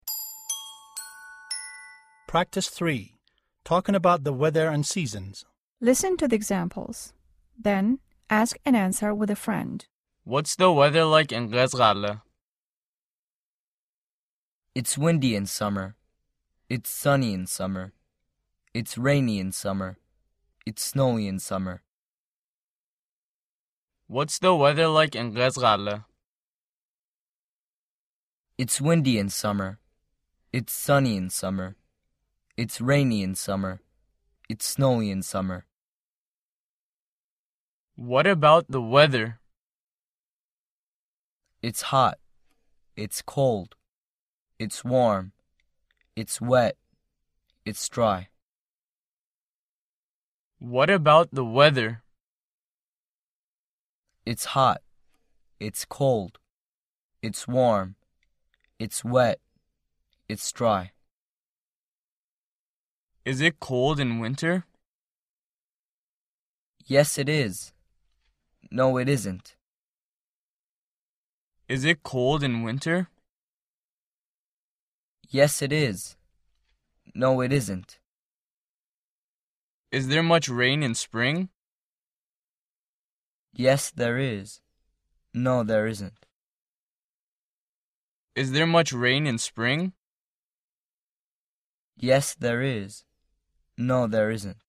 [wc_tab title=”مکالمه آب و هوا”]